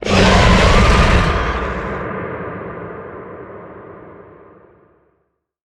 Sfx_creature_squidshark_callout_01.ogg